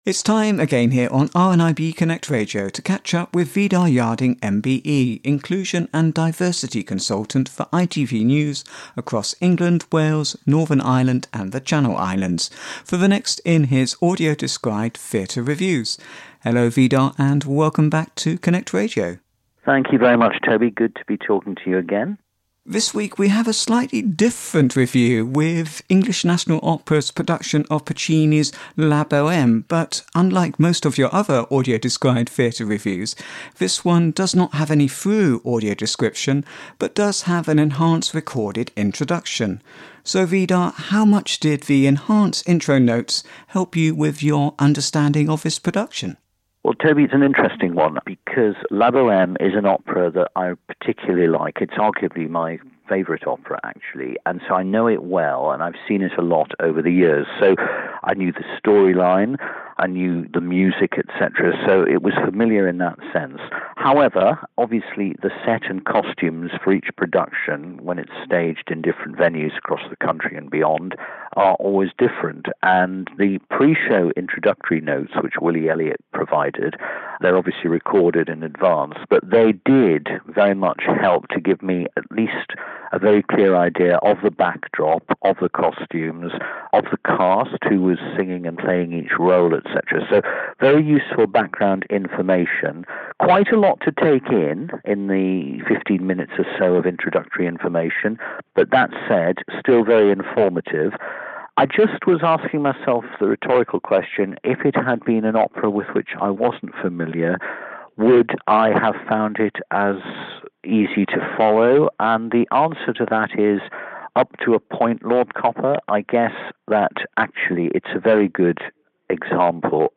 Audio Described Theatre Review